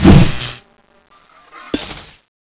midslash1_hit.wav